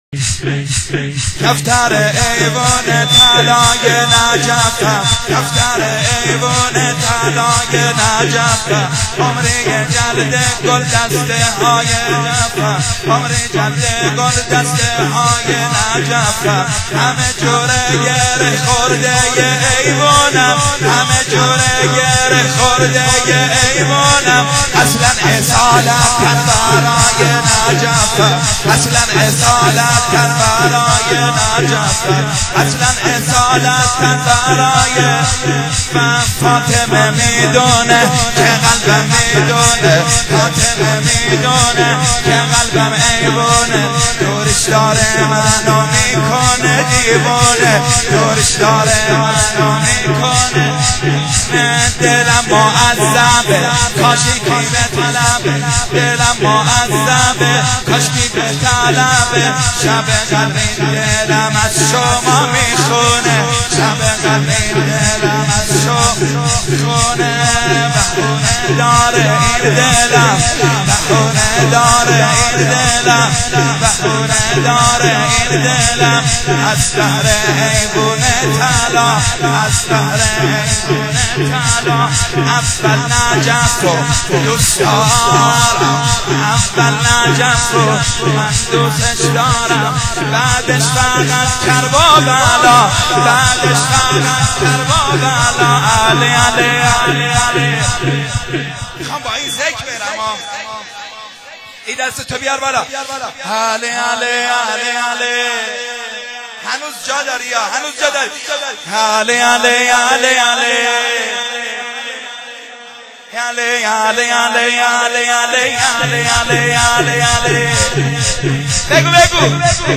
شور2.wma